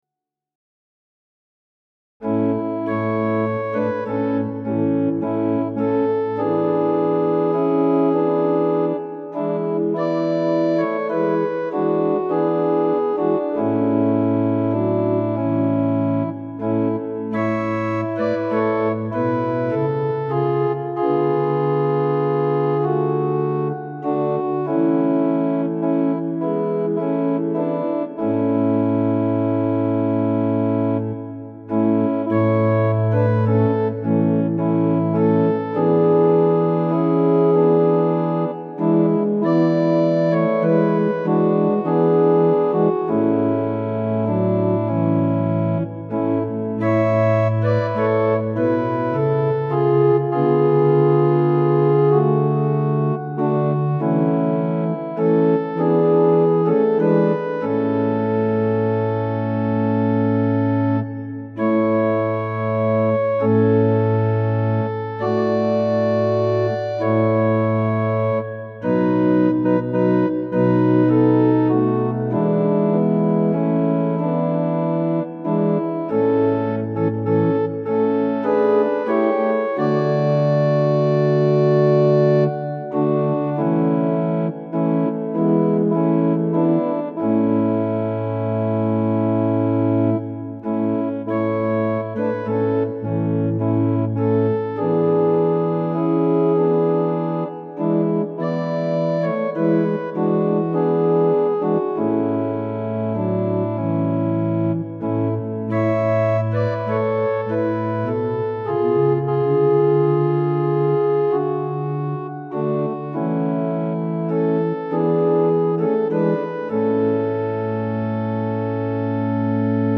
♪賛美用オルガン伴奏音源：
・短い前奏があります
・節により音色、テンポ、和声が変わる場合があります
・間奏は含まれていません
Tonality = A
Pitch = 440
Temperament = Equal